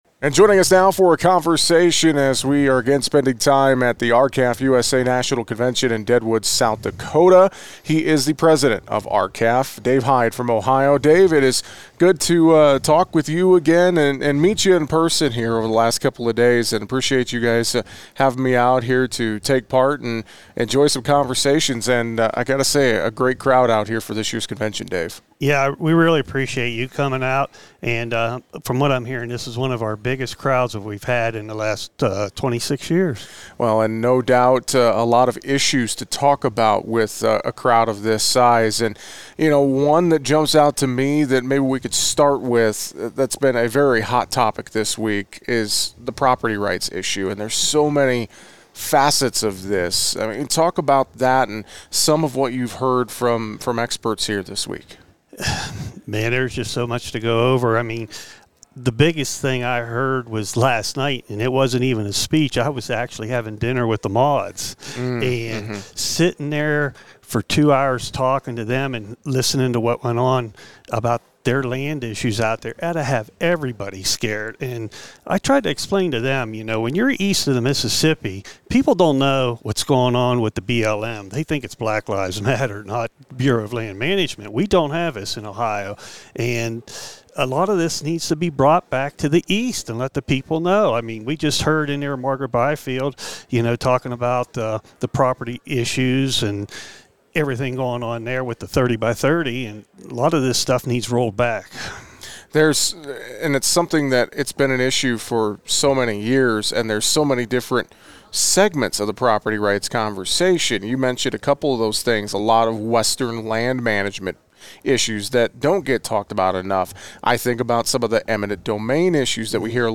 Cattle producers from over 20 states gathered in Deadwood, South Dakota for the 2025 R-CALF USA National Convention.